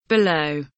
below kelimesinin anlamı, resimli anlatımı ve sesli okunuşu